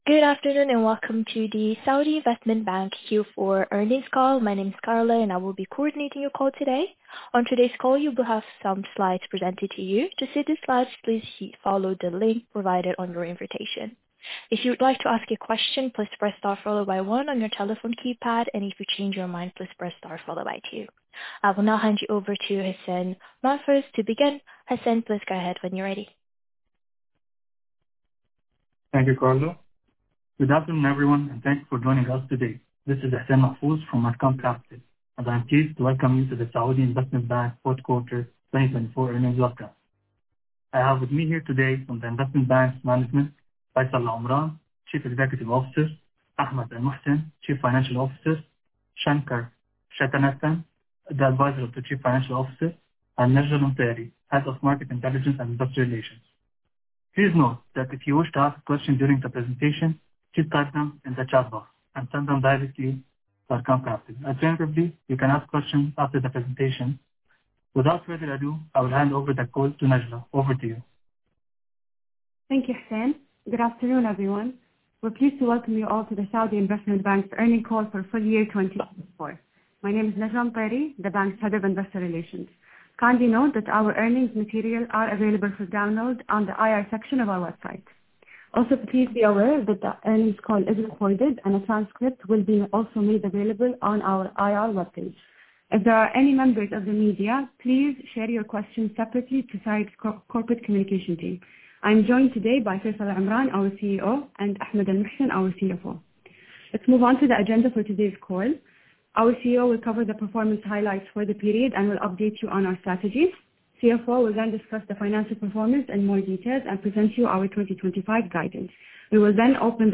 Earnings Call Recording Q1 Q2 Q3 Q4 Earnings Call Transcript Q1 Q2 Q3 Q4 Data Supplement Q1 Q2 Q3 Q4 Earnings Release Q1 Q2 Q3 Q4 Earnings Presentation Q1 Q2 Q3 Q4 Factsheet Q1 Q2 Q3 Q4 Investor Presentation Q1 Q2 Q3 Q4
ArqaamCa-SAIBs-Q4-Earnings-Call.mp3